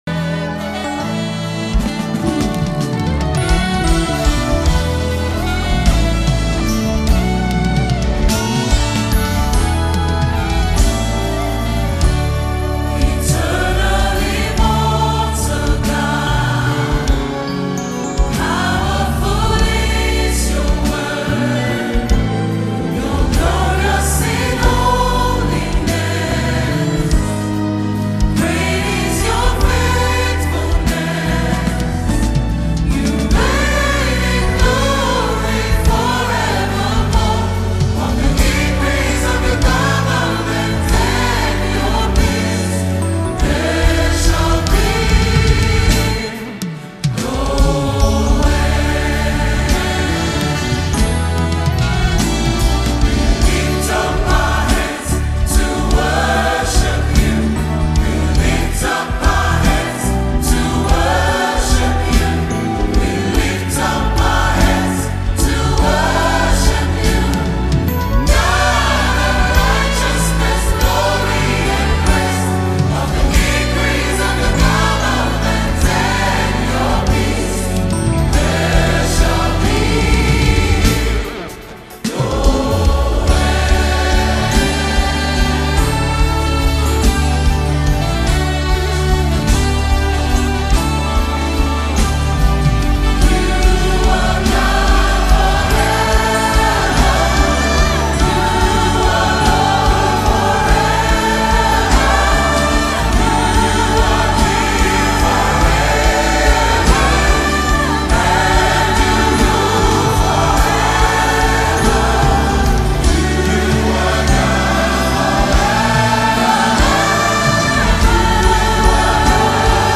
February 11, 2025 Publisher 01 Gospel 0